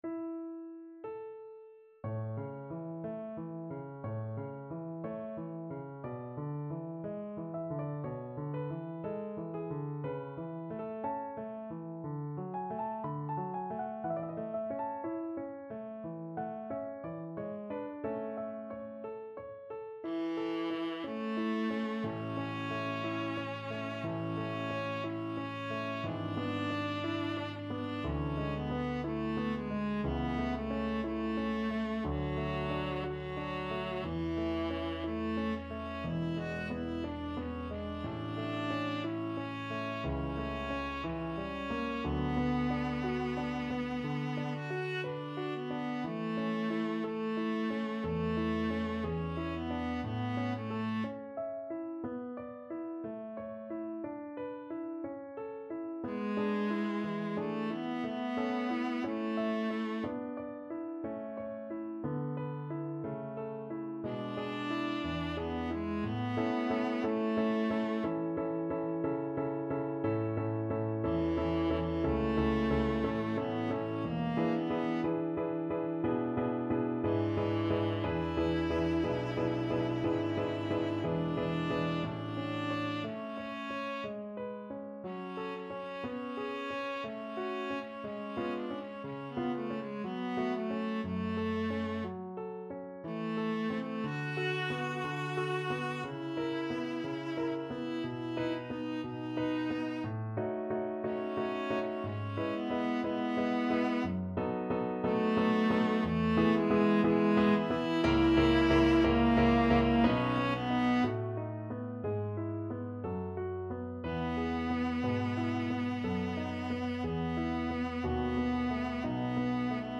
Classical Beethoven, Ludwig van Adelaide, Op.46 Viola version
Viola
D4-Ab5
~ = 60 Larghetto
A major (Sounding Pitch) (View more A major Music for Viola )
4/4 (View more 4/4 Music)
Classical (View more Classical Viola Music)
adelaide-op-46_VLA.mp3